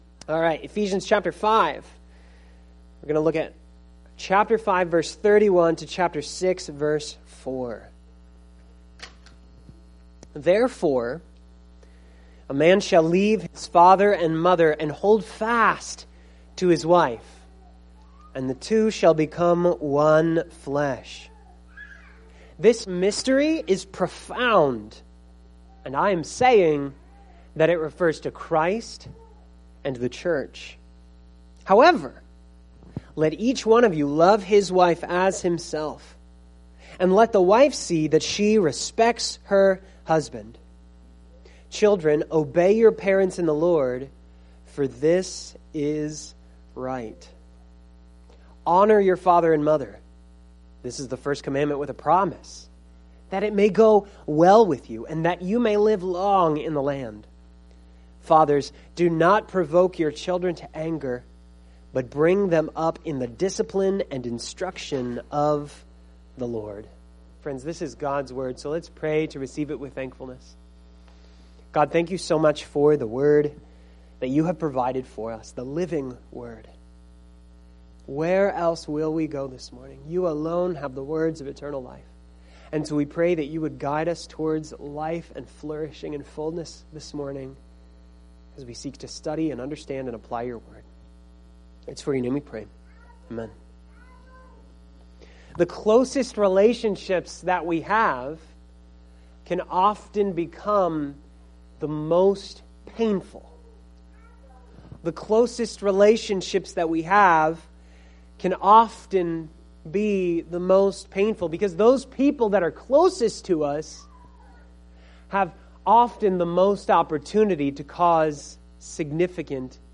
Series: Ephesians — Preacher